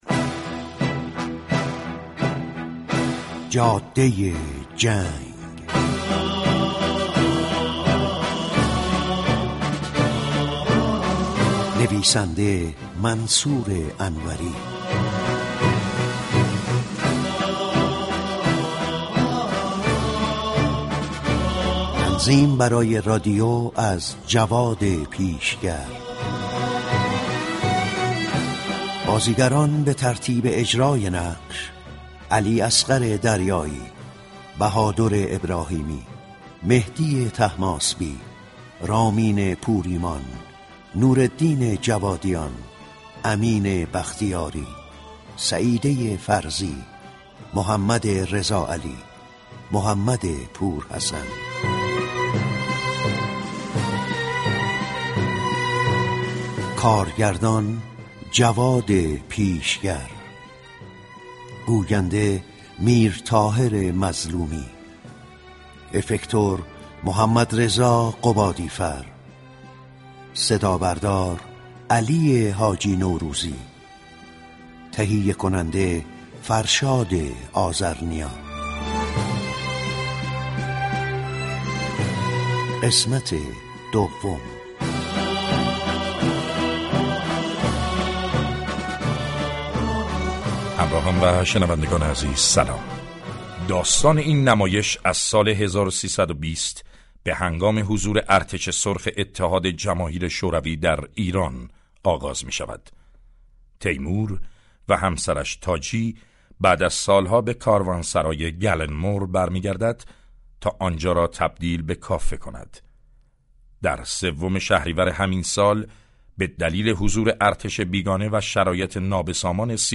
ساختار اجتماعی و شرایط سیاسی جامعه ایران در دهه بیست تا 40 خورشیدی ، در نمایش رادیویی جاده جنگ برای مخاطبان رادیو نمایش شنیدنی شد.